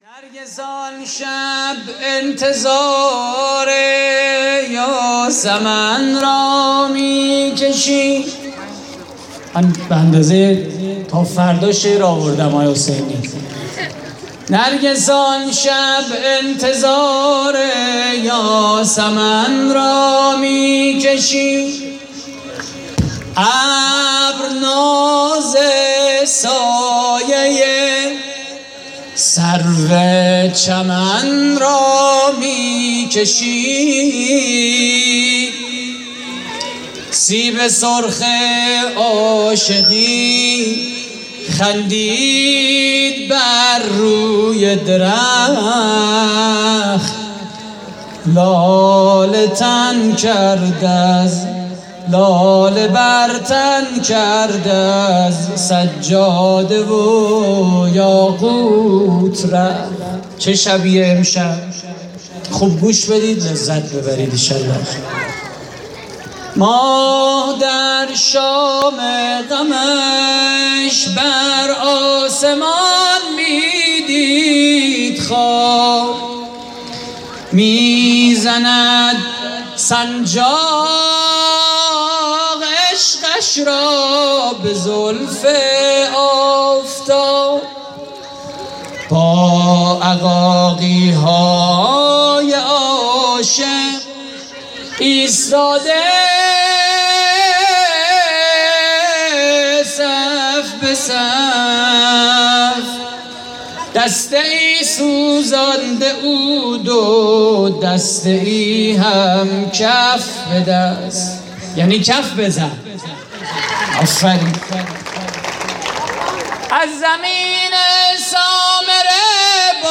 مدیحه: نرگس آن شب انتظار یاسمن را می‌کشید
مراسم جشن خیابانی نیمه شعبان